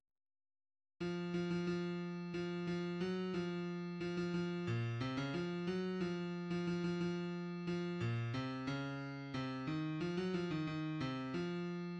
{ \clef bass \tempo 4=90 \key des \major \time 2/4 \set Score.currentBarNumber = #1 \bar "" r4 r8 f8 f16 f16 f4 f8 f ges f4 f16 f f8 bes, c16 des f8 ges f8. f16 f16 f16 f4 f8 bes, c des4 c8 ees f16 ges f ees ees8 c f4 } \addlyrics {\set fontSize = #-2 doggy doogy } \midi{}